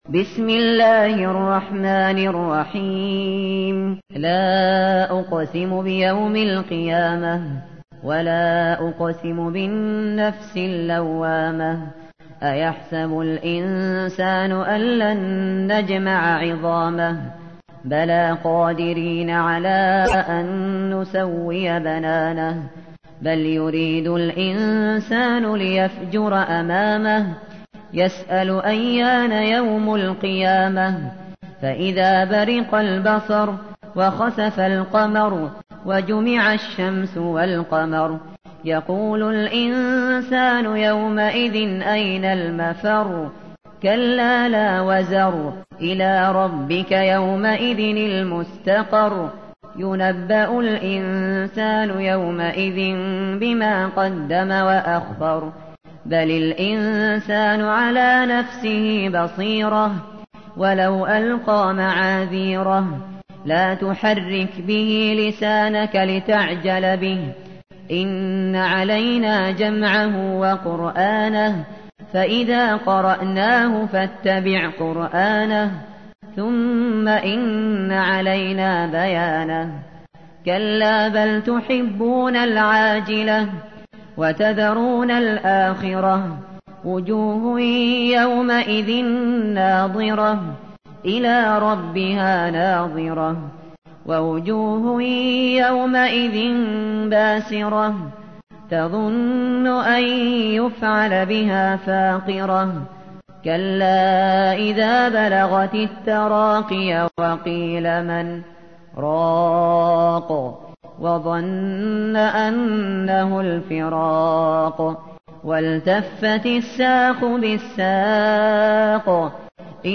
تحميل : 75. سورة القيامة / القارئ الشاطري / القرآن الكريم / موقع يا حسين